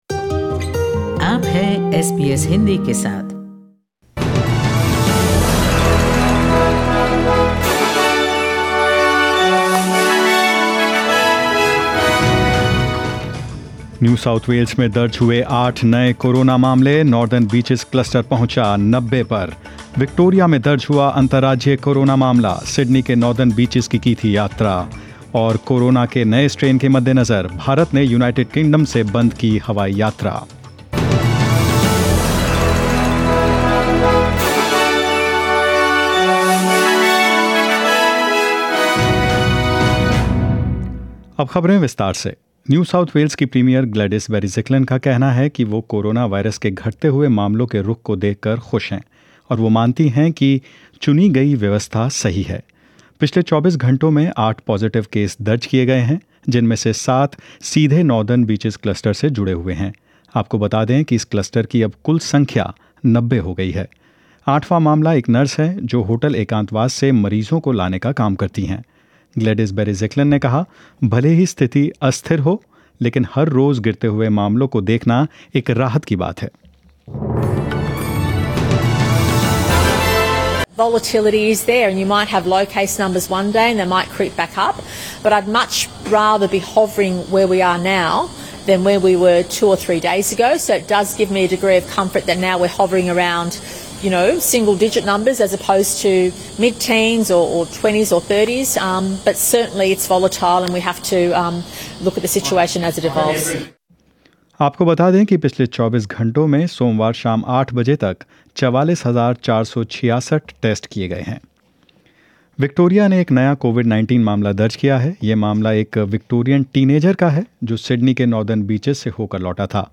News in Hindi - Catch the latest news of 22/12/2020 **The Government of India has announced cancellation of all flights to and from the United Kingdom from December 23 to December 31// ** New South Wales Premier Gladys Berejiklian says the drop in daily COVID-19 cases shows the policy settings are right.